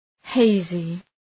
{‘heızı}